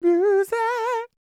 DD FALSET024.wav